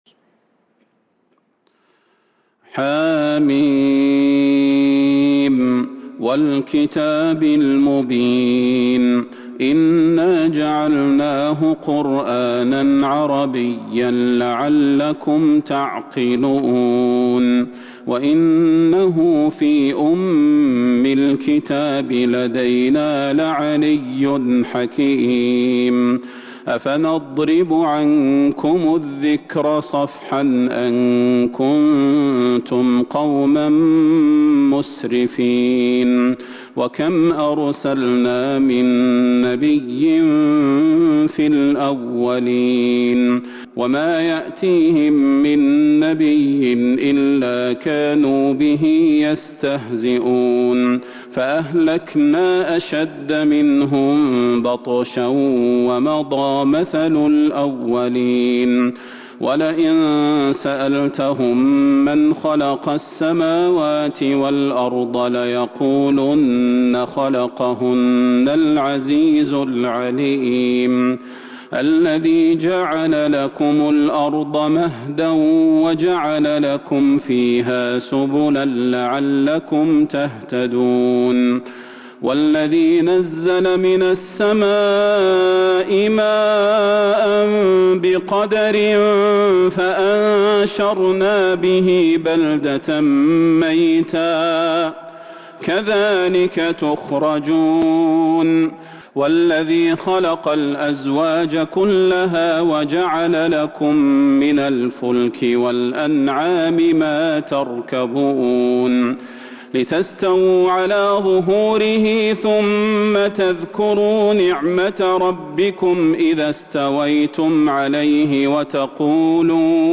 سورة الزخرف Surat Az-Zukhruf من تراويح المسجد النبوي 1442هـ > مصحف تراويح الحرم النبوي عام 1442هـ > المصحف - تلاوات الحرمين